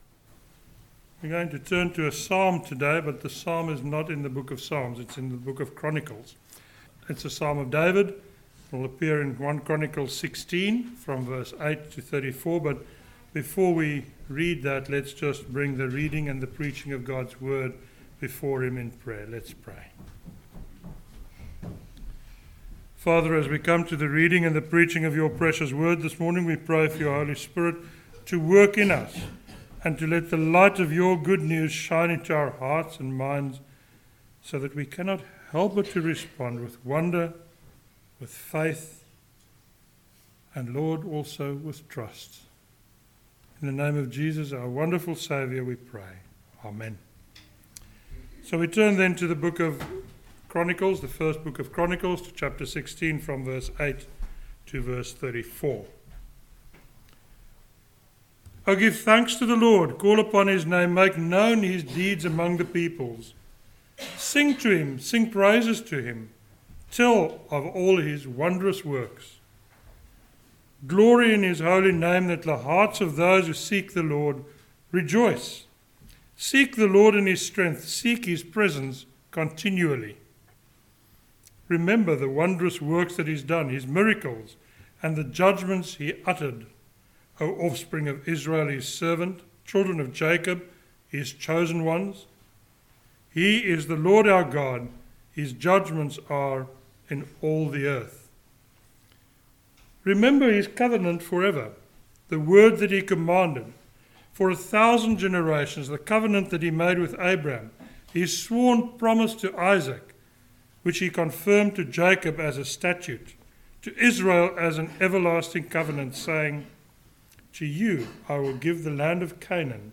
a sermon on 1 Chronicles 16:8-34